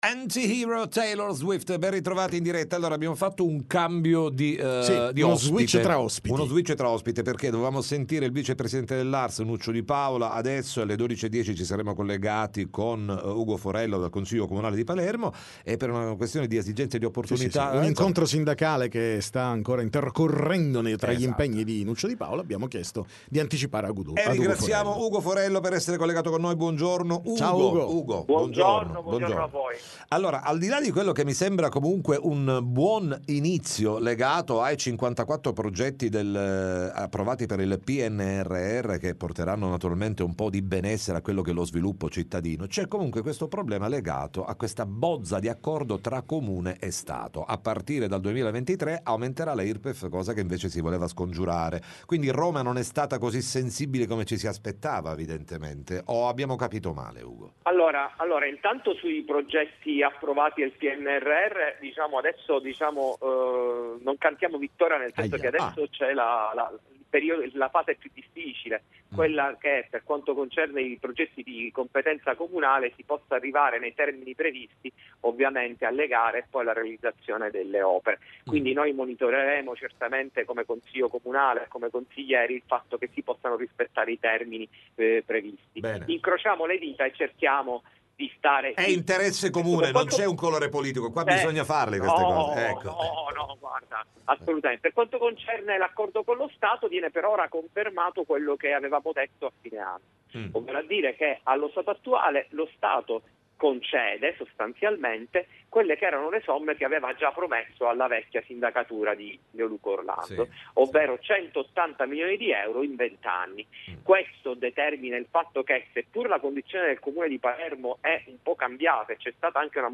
TM Intervista Ugo Forello